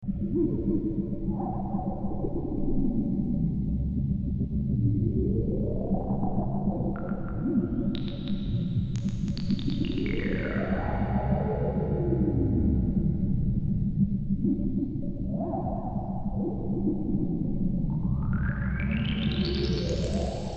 电梯向上移动
描述：电梯向上移动。
Tag: 建筑 电梯 移动 机械 向上